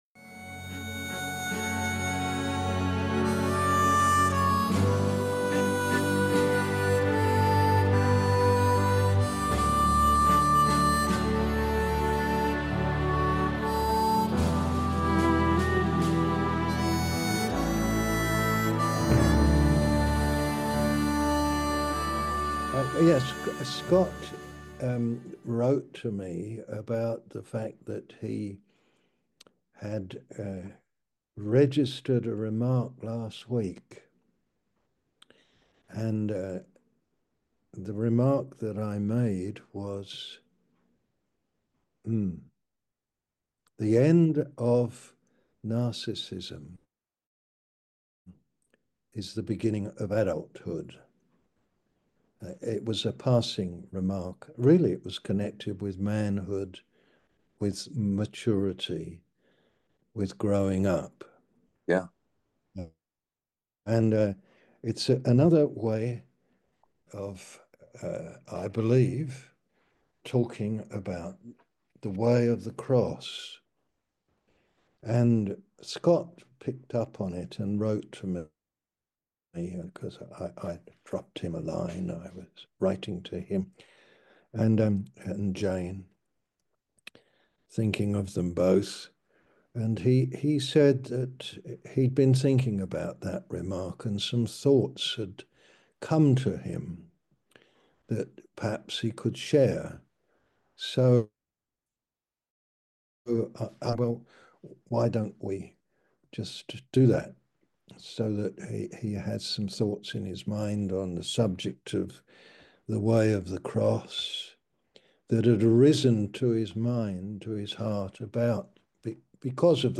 On to Maturity through the Cross - Men's Zoom Gathering